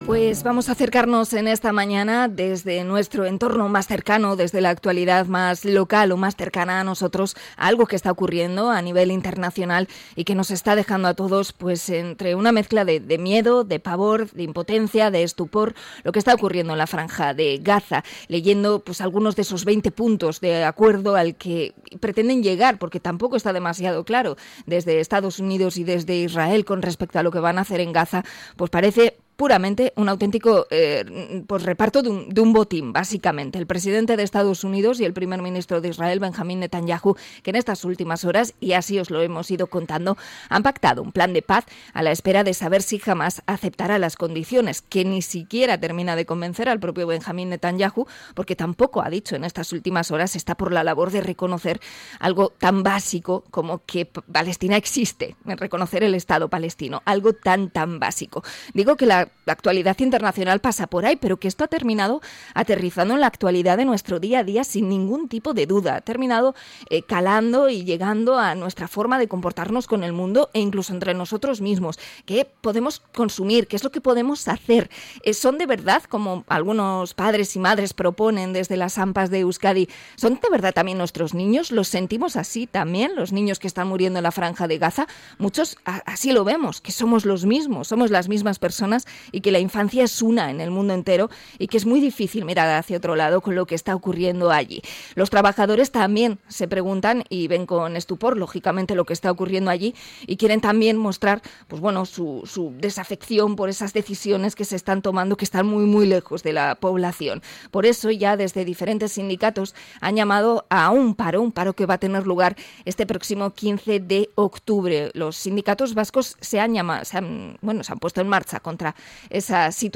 Entrevista a ELA por el papel de los trabajadores de CAF contra la presencia de la empresa en territorios ocupados